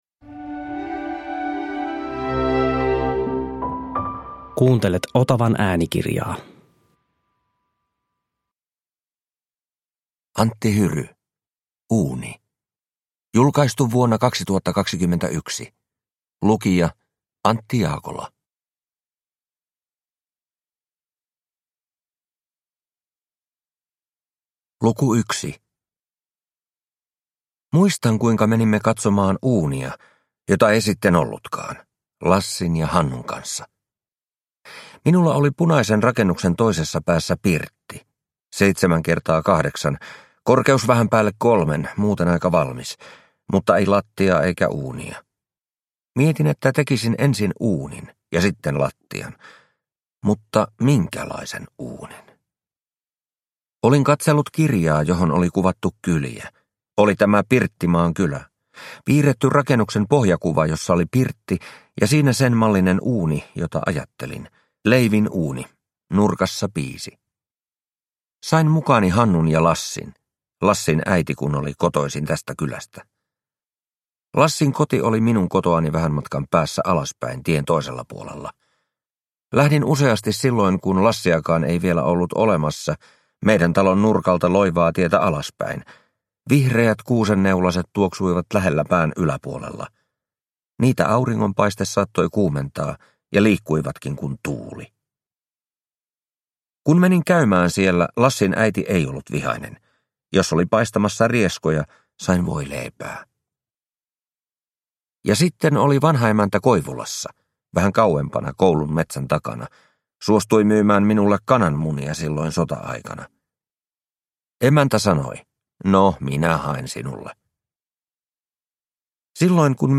Uuni – Ljudbok – Laddas ner